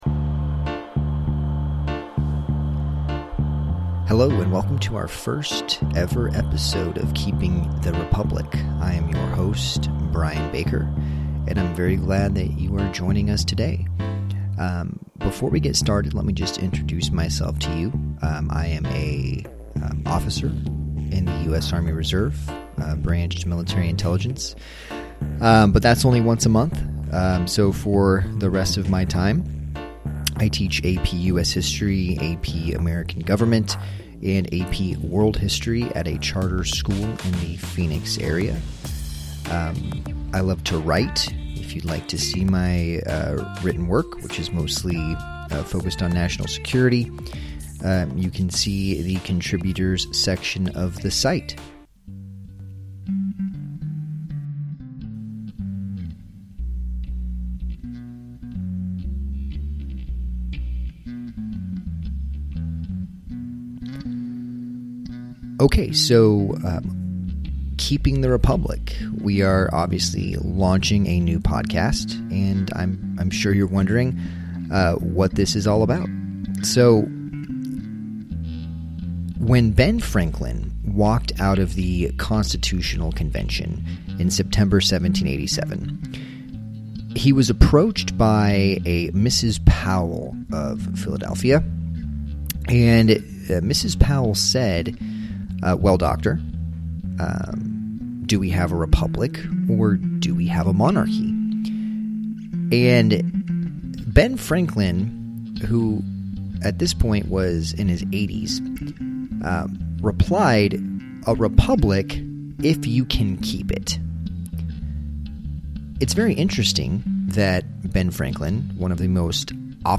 Creative commons music featured in this episode